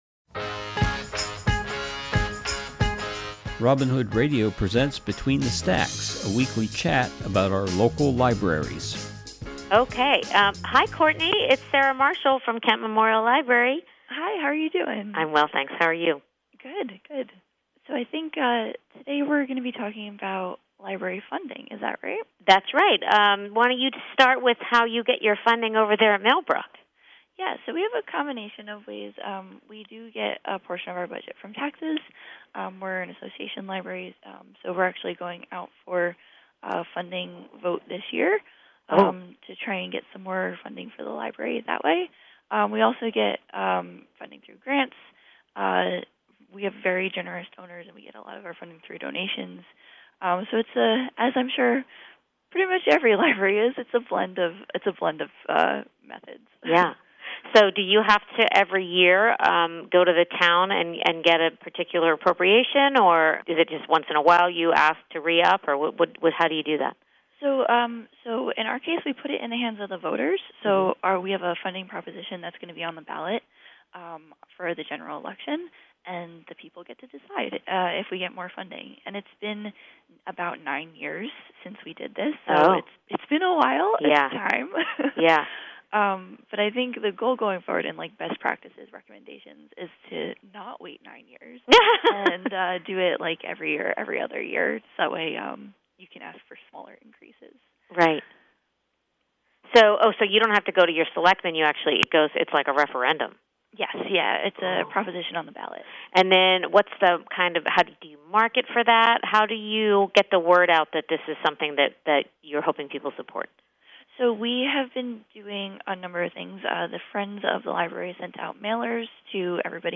This week’s program is a conversation